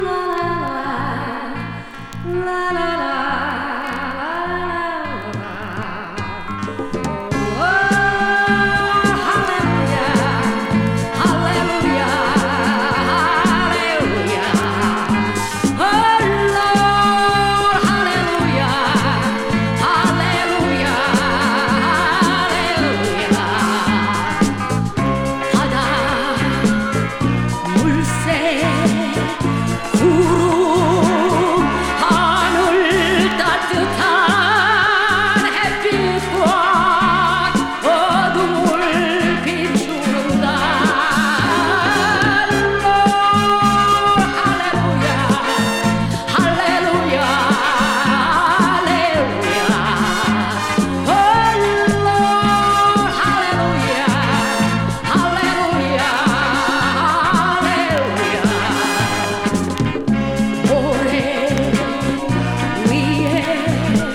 スリリングな演奏と、エモーショナルなヴォーカルが鬼気迫る